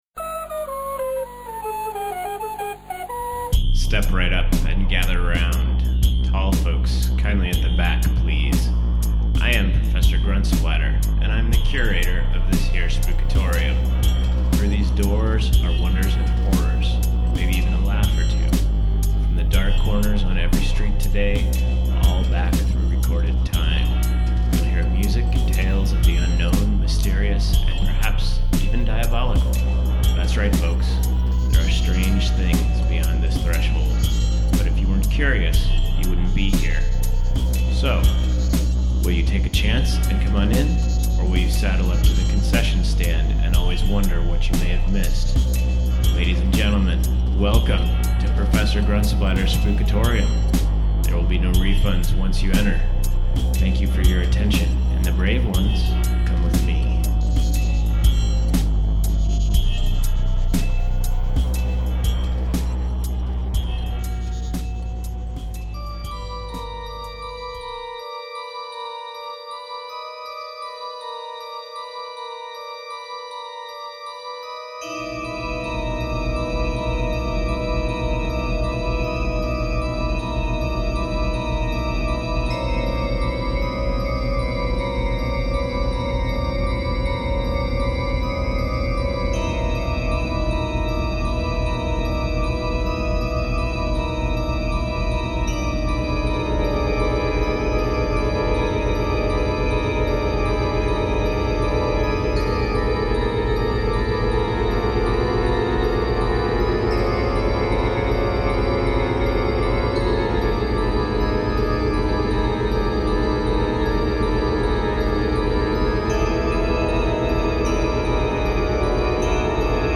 Aside from that there is just a whole lot of music.